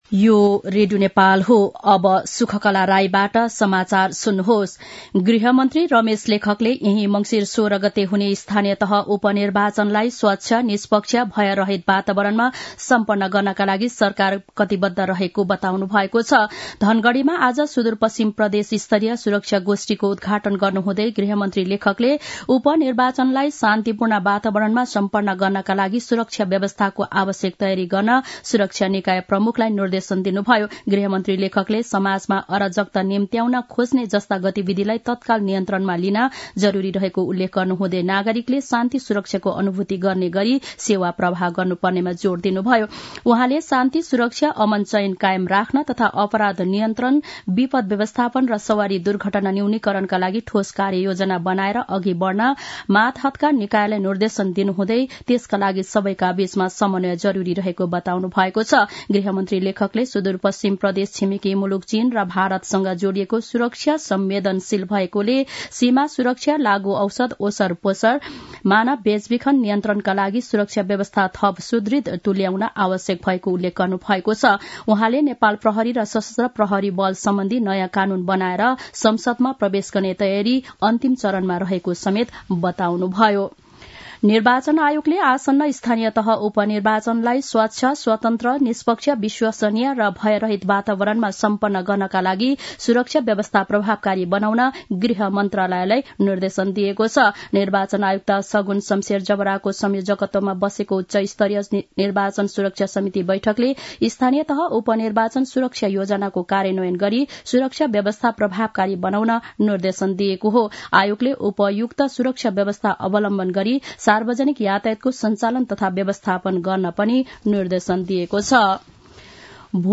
दिउँसो १ बजेको नेपाली समाचार : ५ मंसिर , २०८१
1-pm-nepali-news-1-3.mp3